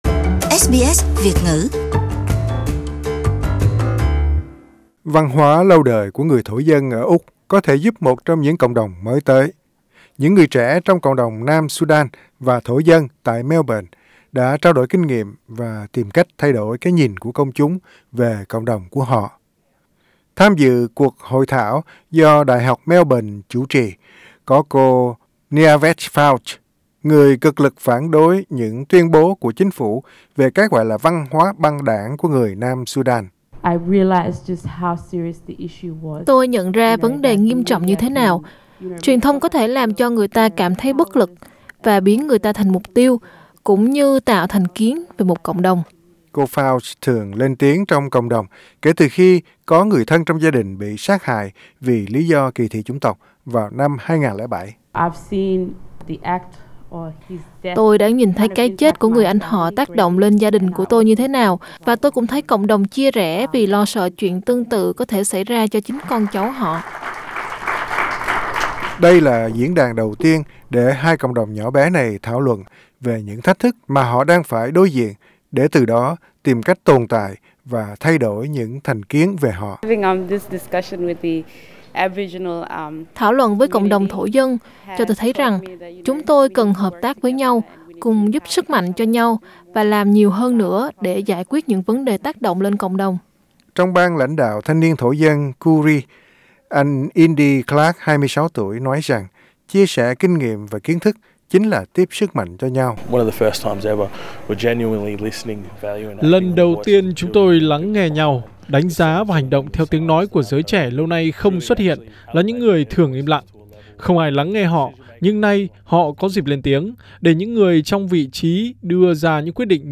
Panellists discuss racism and resilience in Melbourne Source: SBS